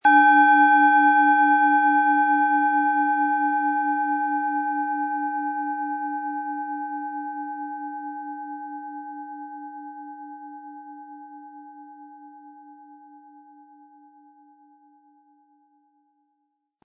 Planetenton 1
Von Hand getriebene Klangschale mit dem Planetenklang Saturn aus einer kleinen traditionellen Manufaktur.
SchalenformBihar
MaterialBronze